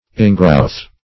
Search Result for " ingrowth" : Wordnet 3.0 NOUN (1) 1. something that grows inward ; The Collaborative International Dictionary of English v.0.48: Ingrowth \In"growth`\, n. A growth or development inward.